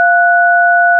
DTMF keypad frequencies (with sound clips)[12]
1209 Hz 1336 Hz 1477 Hz 1633 Hz